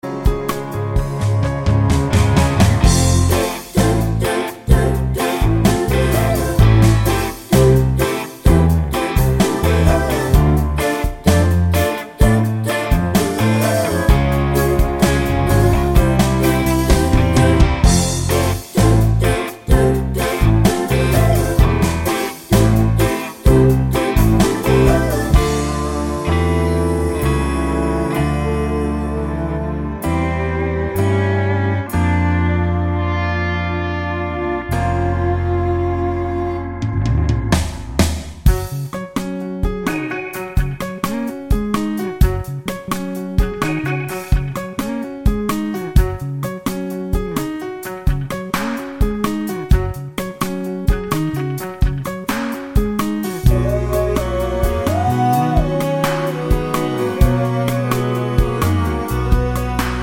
no Backing Vocals Musicals 3:30 Buy £1.50